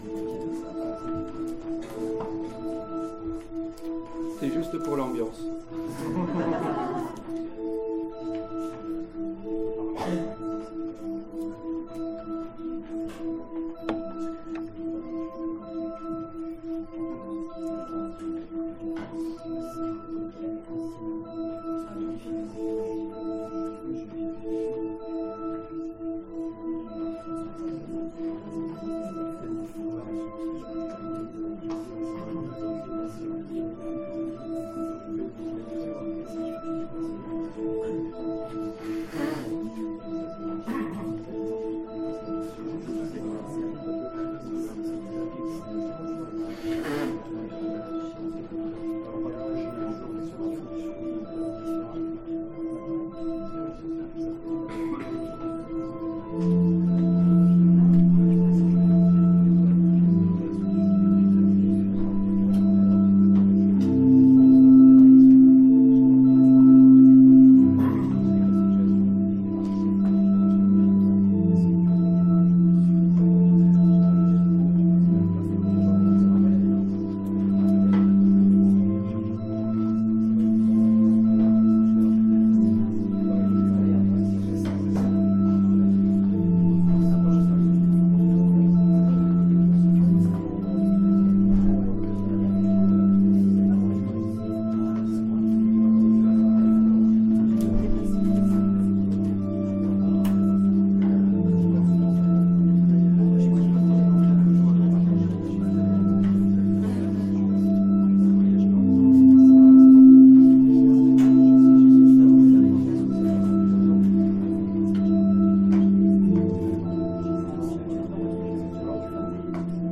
Le cours était entrecoupé de projections d'extraits, ce qui peut expliquer parfois le manque d'intelligibilité.
Conférence